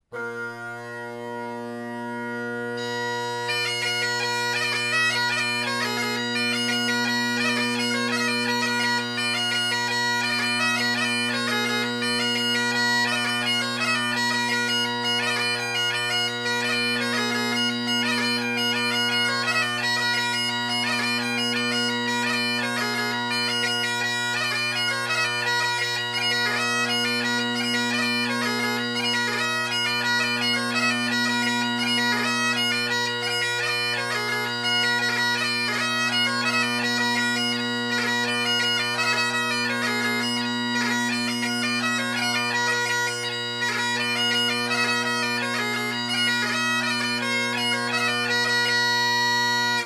4 part reel